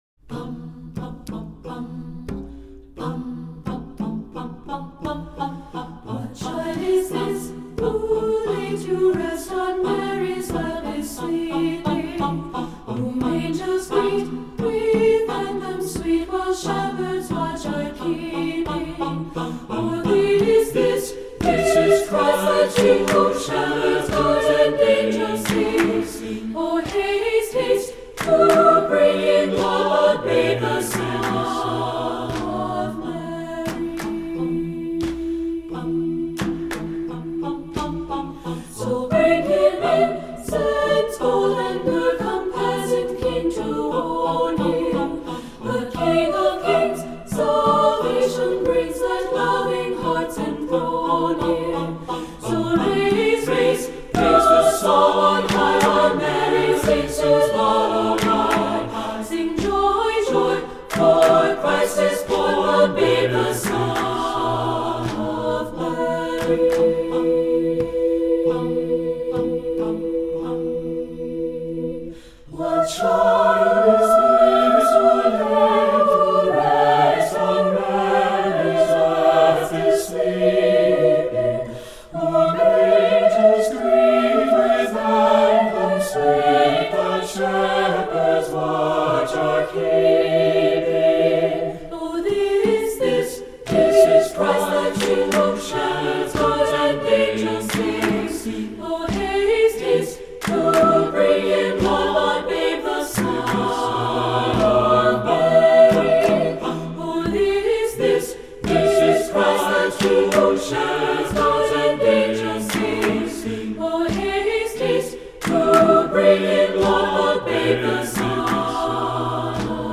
Voicing: SSAB a cappella with optional percussion